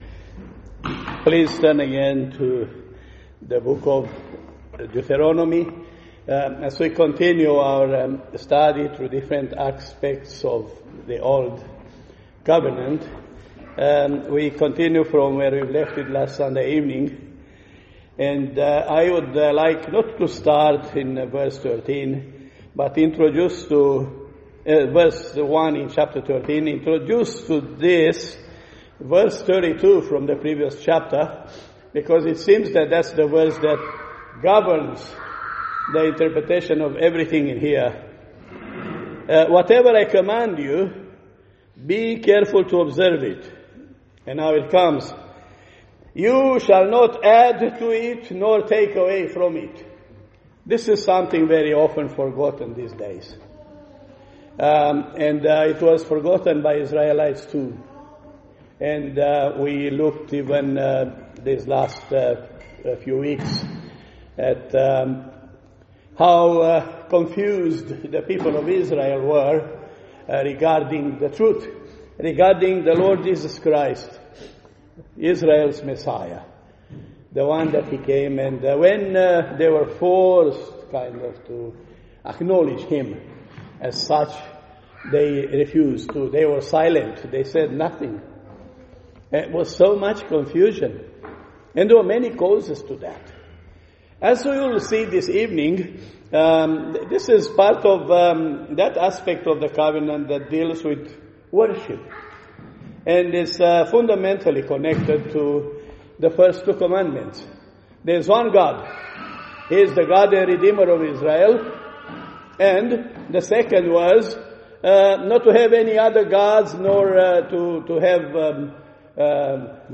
Creation and Covenant Passage: Deuteronomy 13:1-18 Service Type: Sunday Evening « The Last Sermon of Jesus The Last Sermon of Jesus